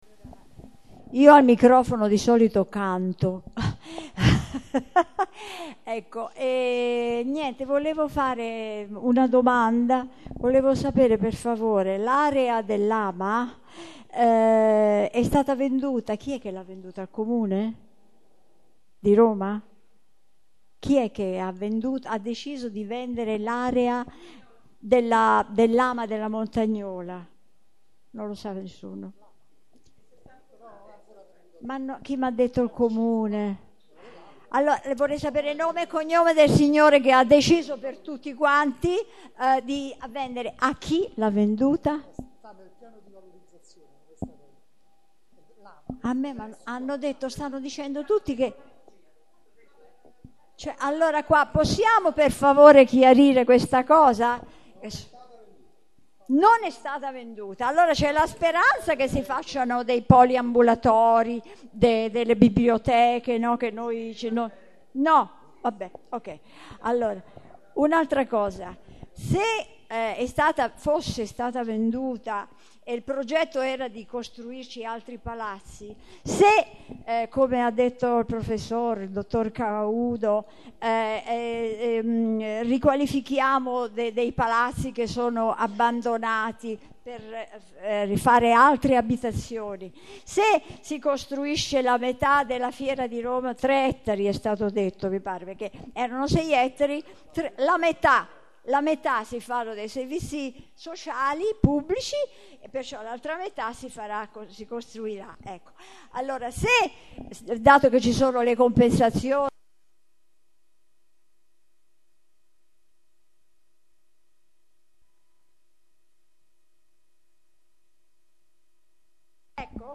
Registrazione integrale dell'incontro svoltosi il 7 luglio 2014 presso l'Urban Center di Via Niccolò Odero